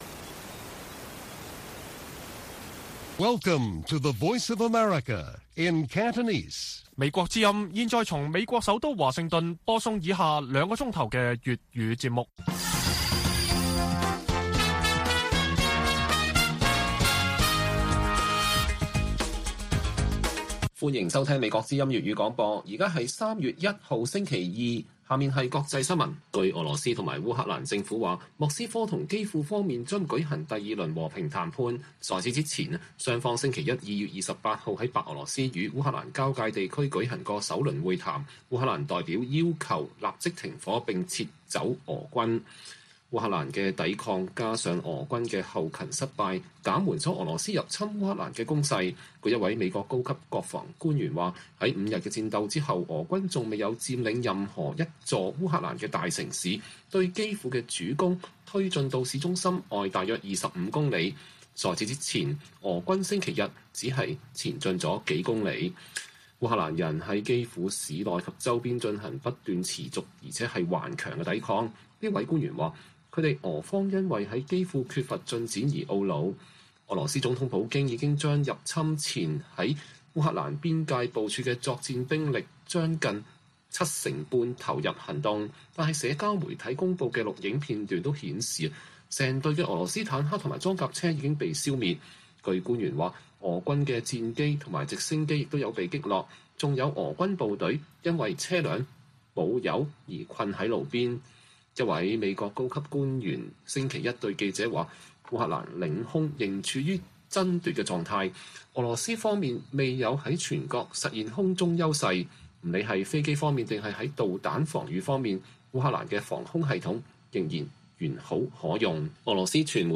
粵語新聞 晚上9-10點: 烏俄準備第二輪和談 俄軍攻勢遇挫損失慘重